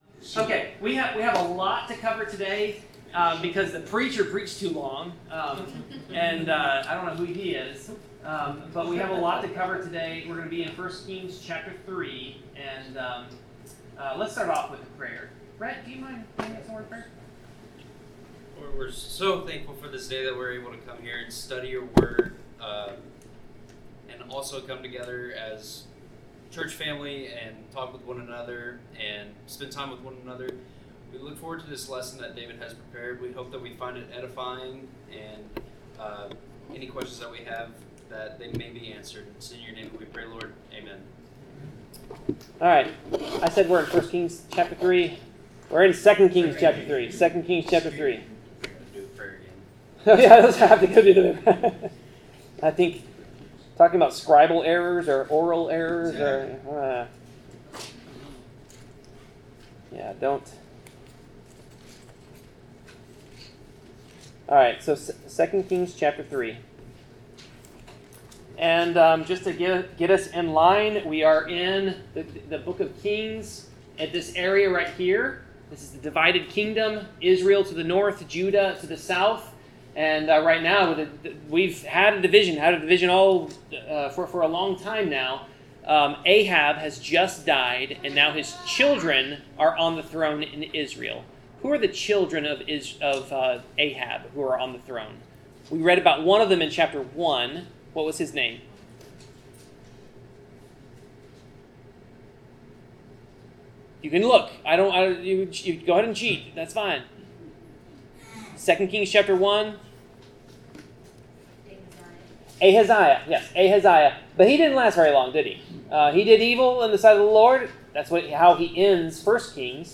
Bible class: 2 Kings 3
Passage: 2 Kings 3 Service Type: Bible Class